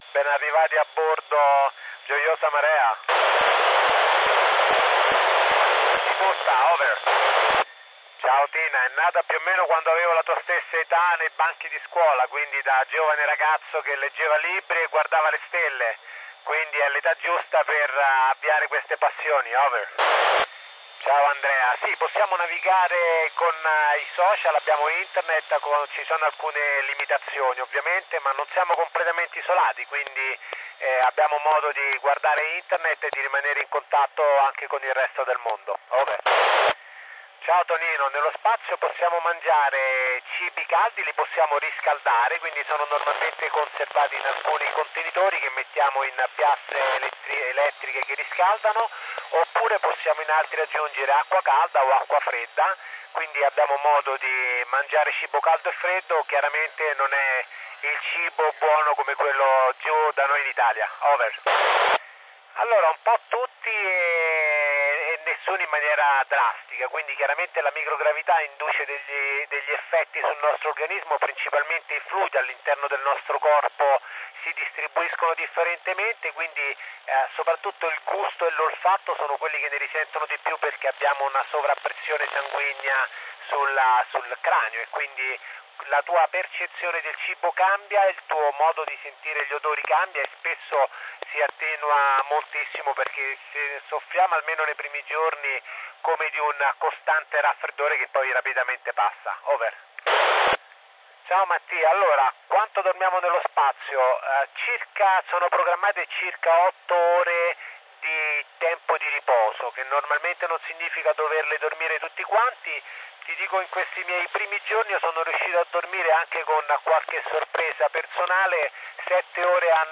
Mes réceptions de l'ISS.